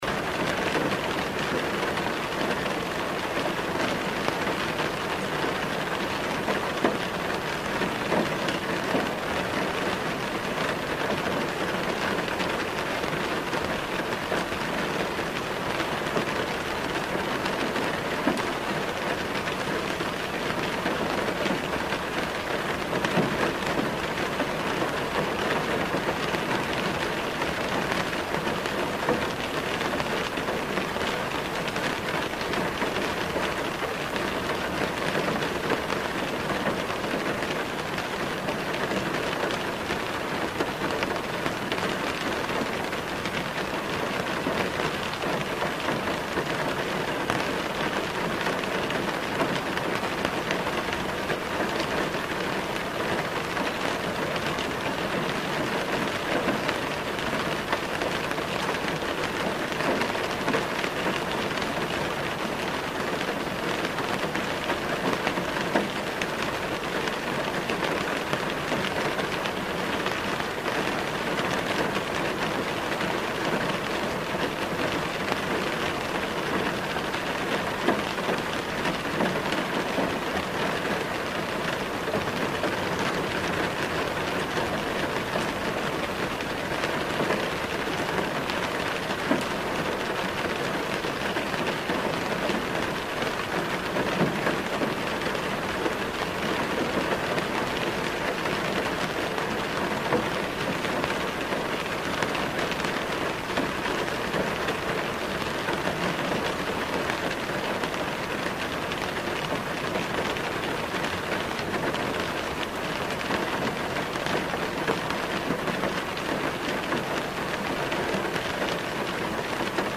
Звуки для сна
Звук дождя в машине